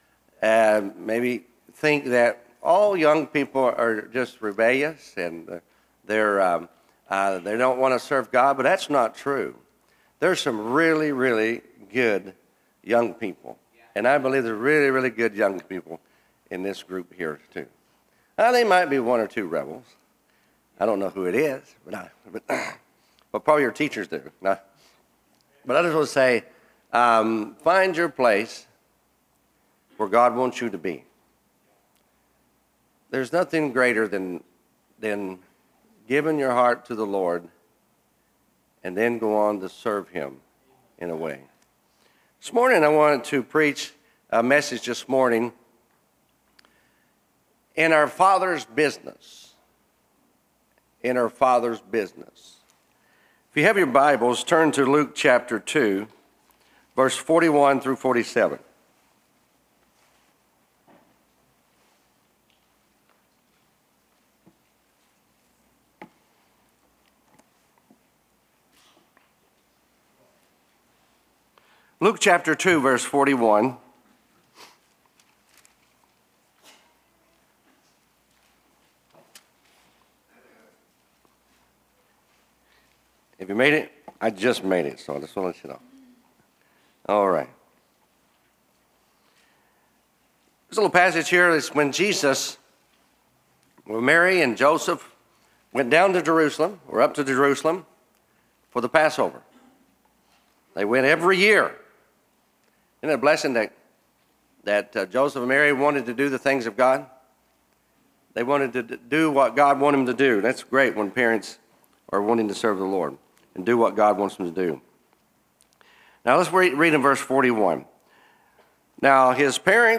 Series: (Missions Conference 2025)
Preacher